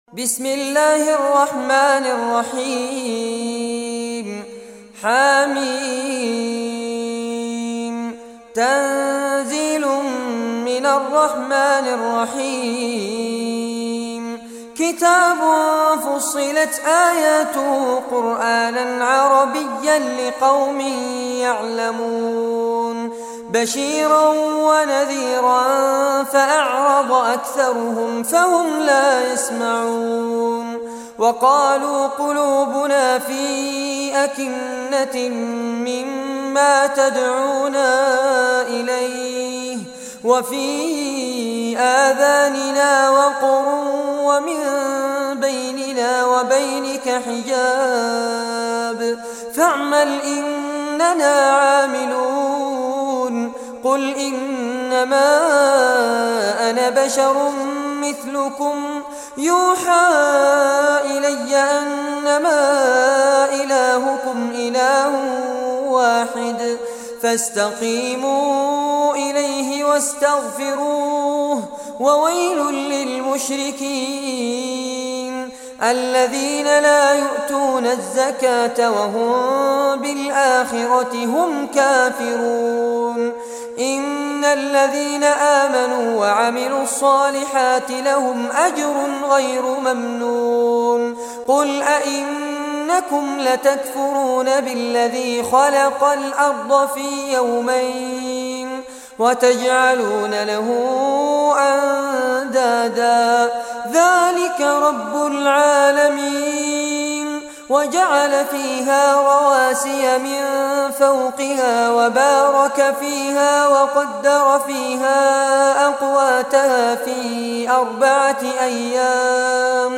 Surah Fussilat Recitation by Fares Abbad
Surah Fussilat, listen or play online mp3 tilawat / recitation in Arabic in the beautiful voice of Sheikh Fares Abbad.